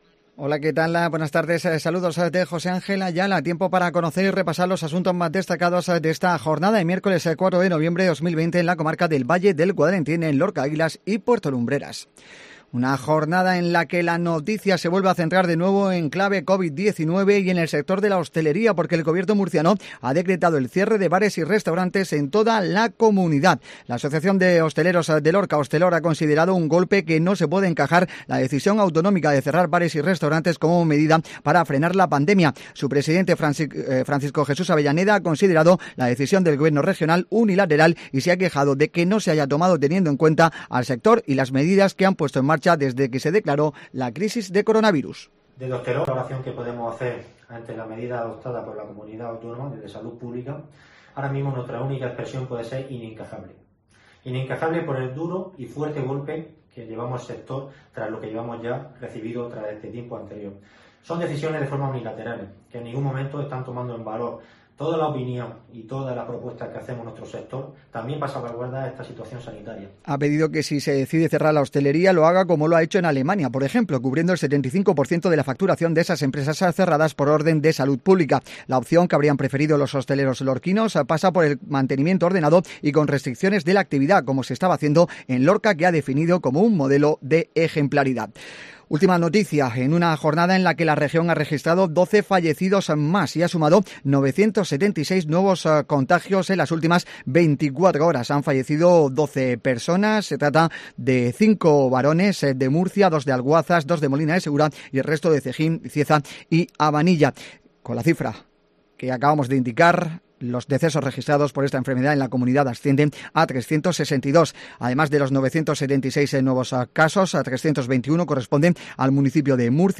AUDIO: INFORMATIVO MEDIODÍA COPE LORCA